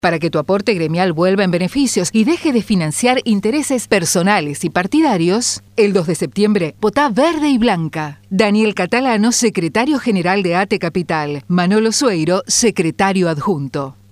ATE LISTA VERDE Y BLANCA // spot radial de campaña (03)